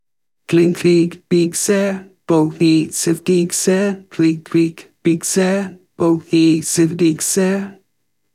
Klik w pixe Debiutancki mój utwór w klimacie rap/hip-hop/trap.